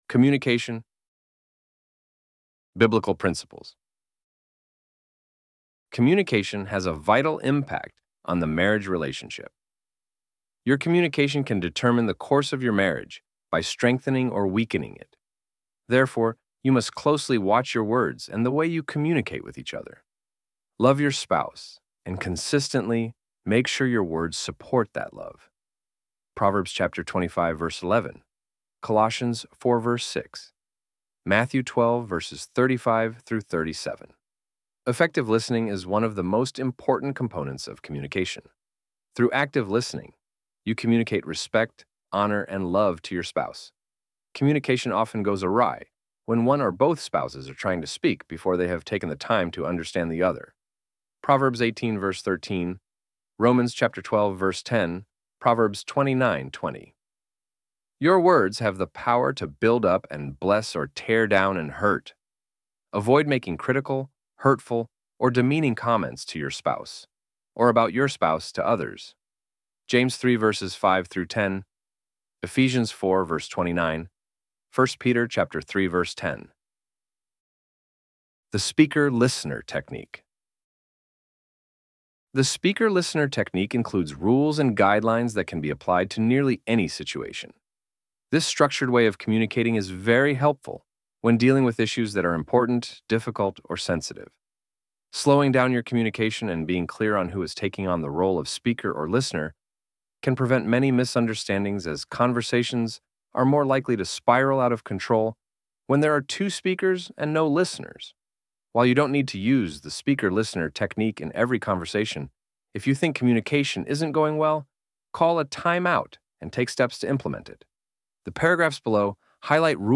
ElevenLabs_Communication_in_Marriage_2025.pdf.mp3